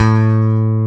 Index of /90_sSampleCDs/Roland L-CD701/BS _E.Bass 2/BS _Rock Bass
BS  ROCKBSA3.wav